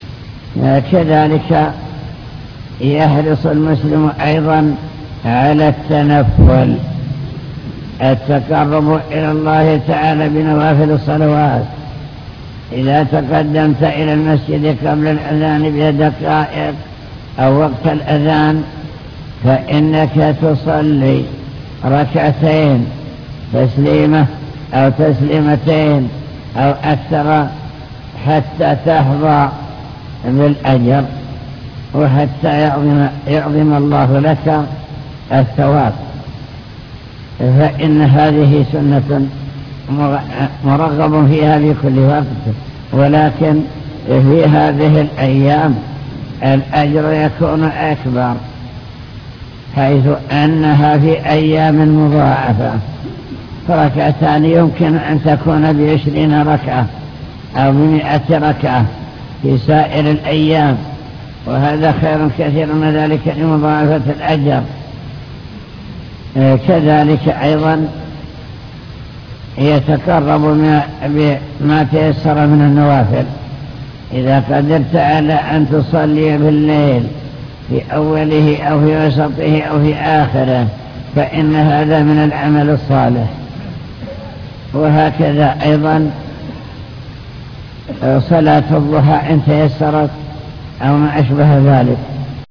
المكتبة الصوتية  تسجيلات - لقاءات  اللقاء المفتوح